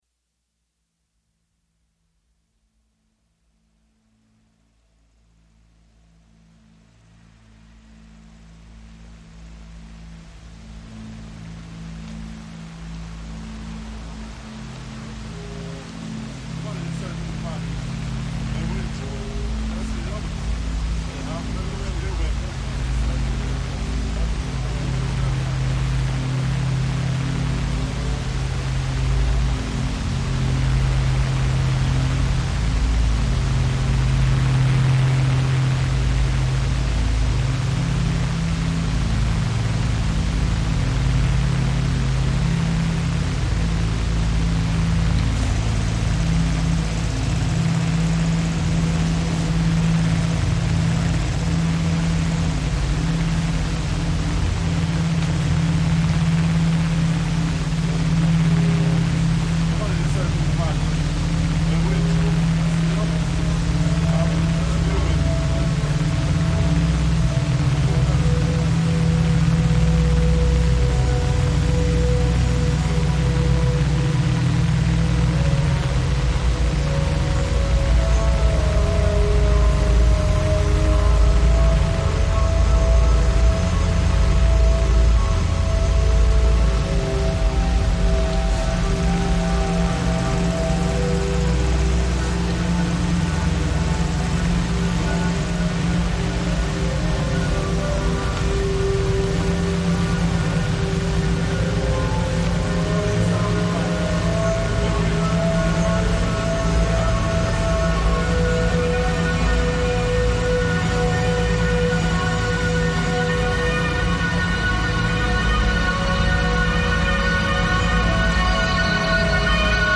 San Lorenzo, Rome reimagined